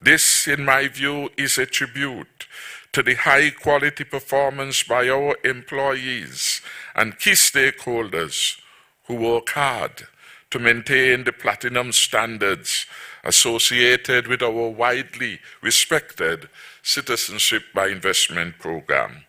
Prime Minster and Minister of Finance, Dr. Timothy Harris, made this comment on the recent ranking: